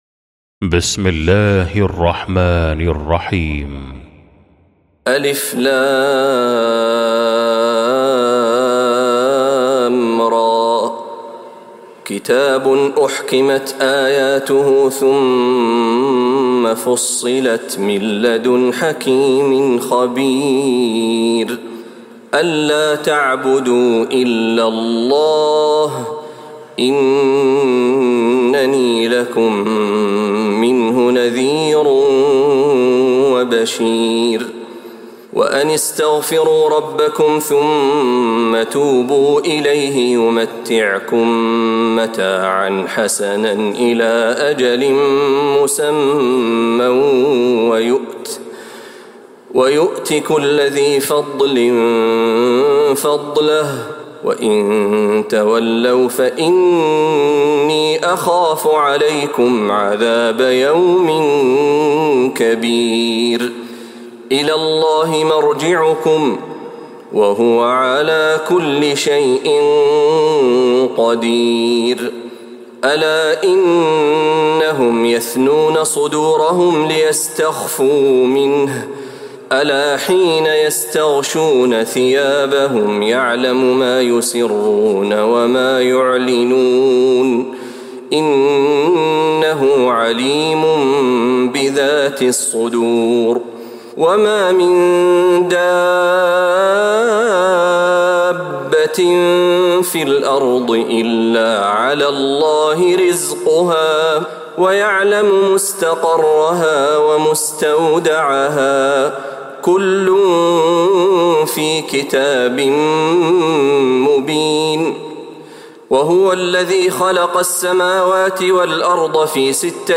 سورة هود | Surah Hud > مصحف تراويح الحرم النبوي عام 1446هـ > المصحف - تلاوات الحرمين